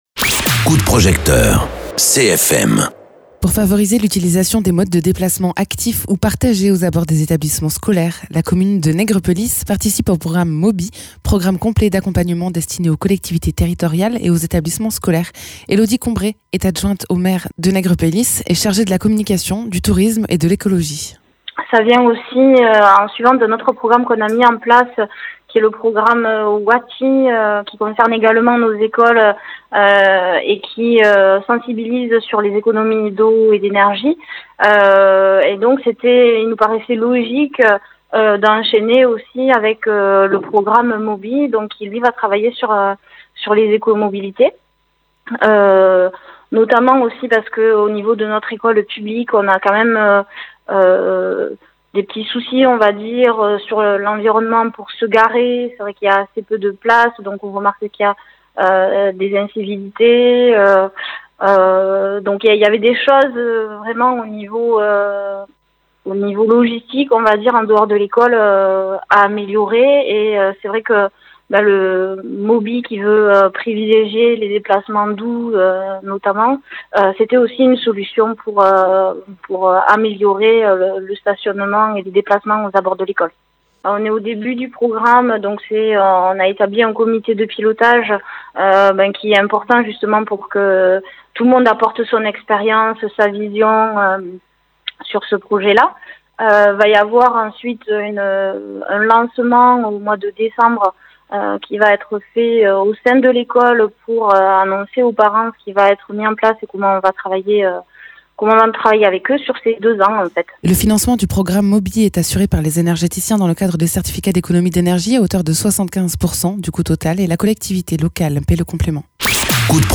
Interviews
Invité(s) : Elodie Combret, adjointe au maire de negrepelisse et chargée de la communication, tourisme et écologie